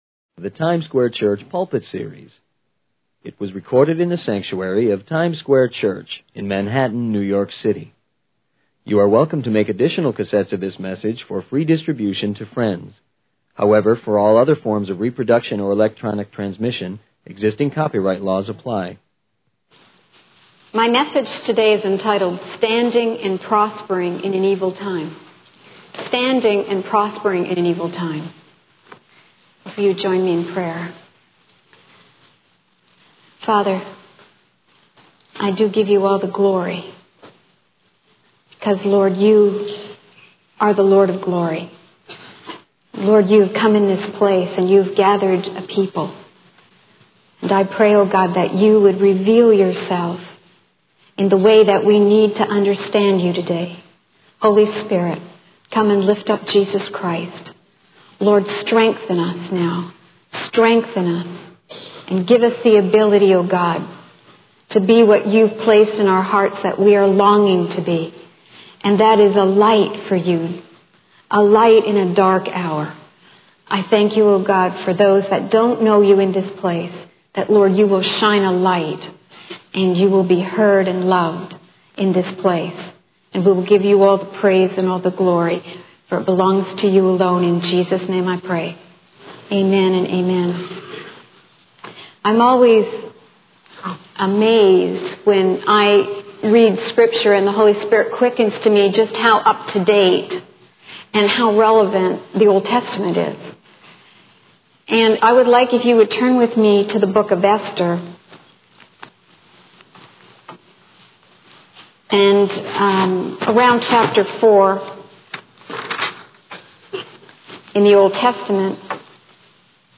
In this sermon entitled 'Standing and Prospering in an Evil Time,' the speaker focuses on the book of Esther in the Old Testament.